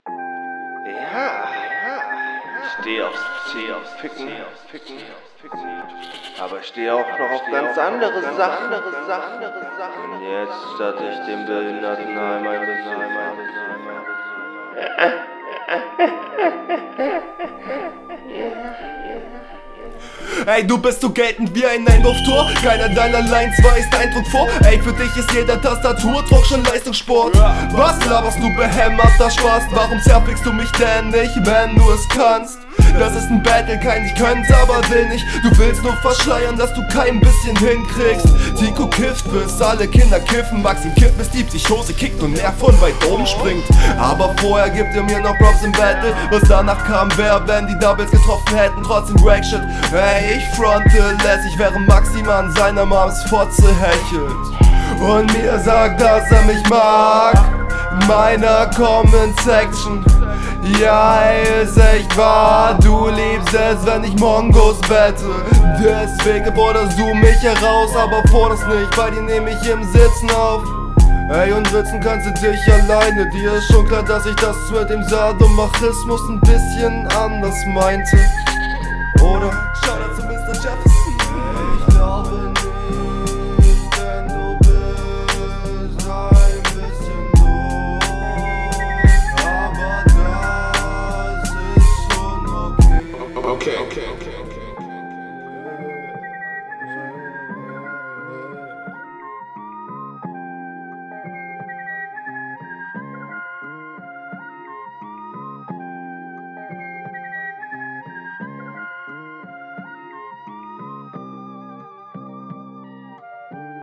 Coole Runde, kommst auf jeden Fall souveräner auf dem Beat als dein Gegner.
deine stimme kommt direkt schonmal viel schöner hervor und du rapst das auch recht cool.